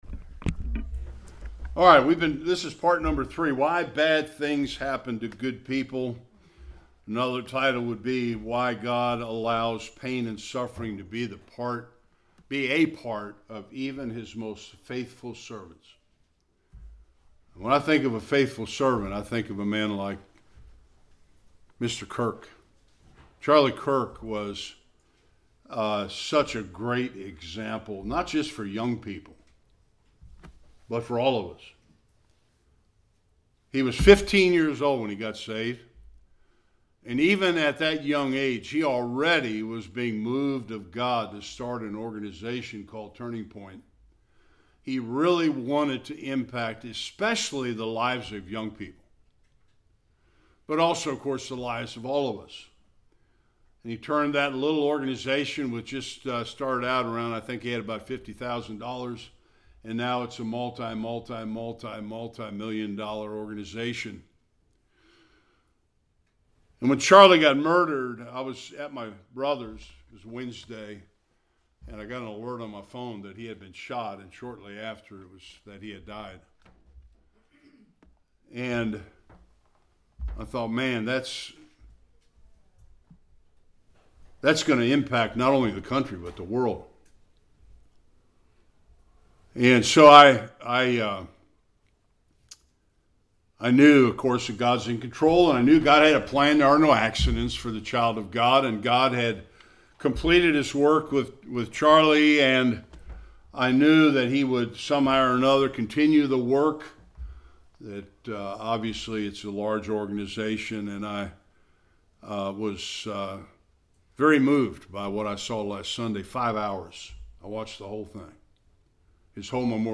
Various Passages Service Type: Sunday Worship In Part 3